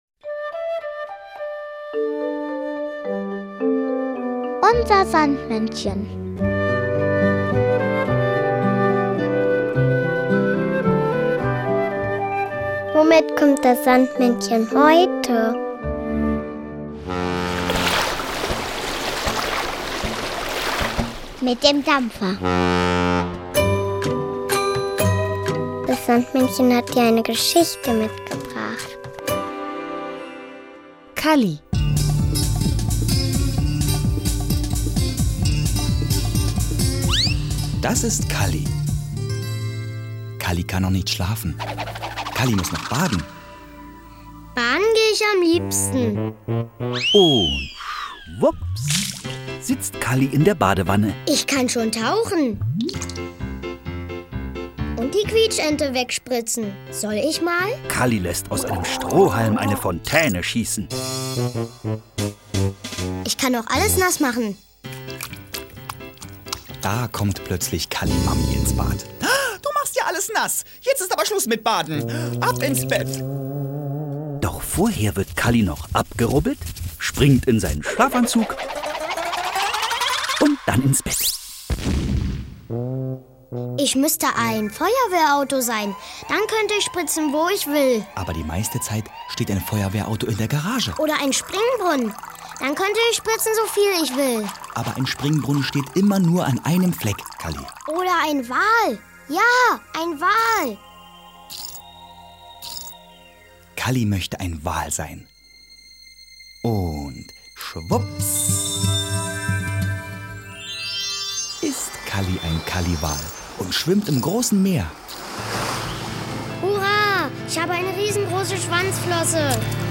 Unser Sandmännchen: Geschichten und Lieder 42 ~ Unser Sandmännchen Podcast